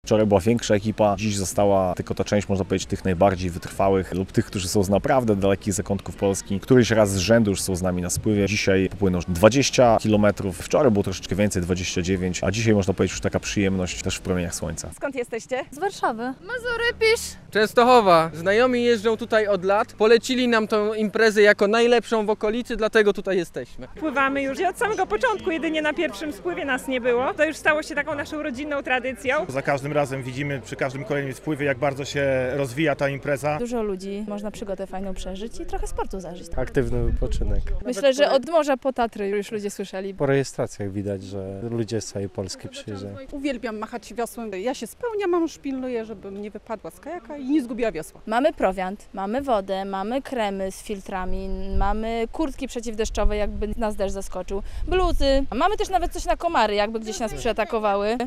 Drugi dzień spływu "500 kajaków" - relacja